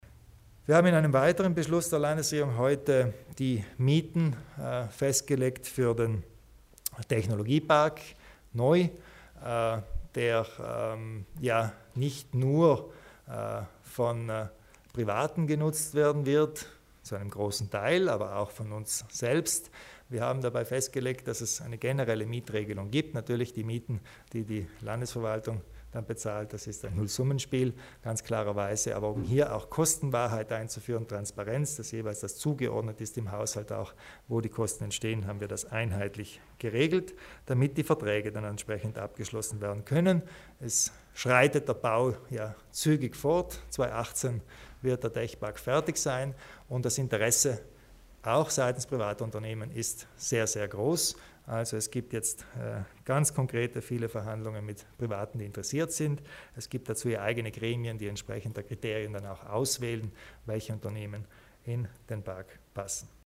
Landeshauptmann Kompatscher erläutert die Kriterien für die Mietpreise im NOI